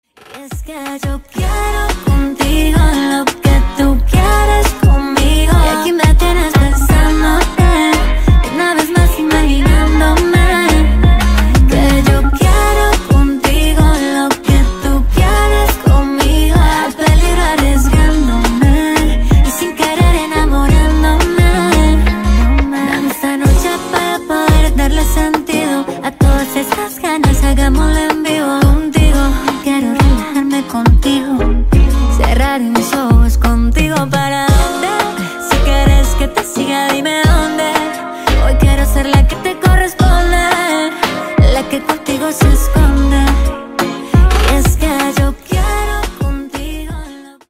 música Pop